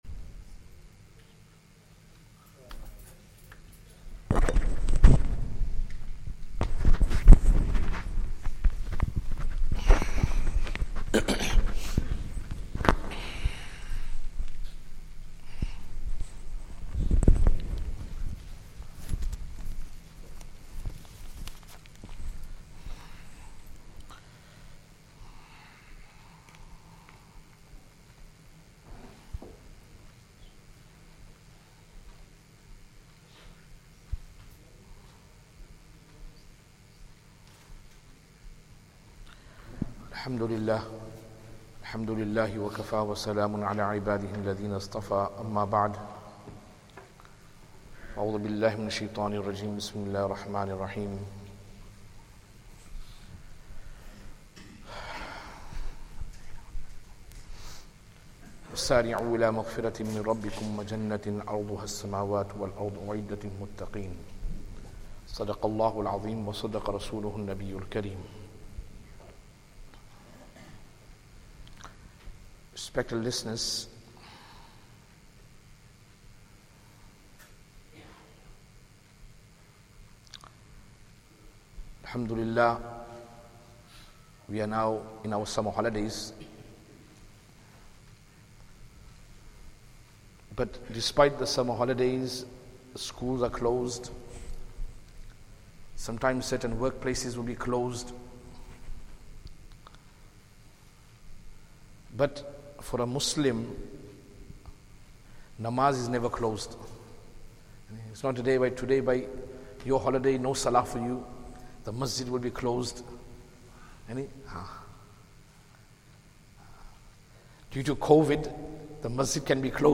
Masjid Al Farouq | Jumuah
Masjid Al Farouq, Walsall